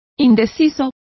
Complete with pronunciation of the translation of undecided.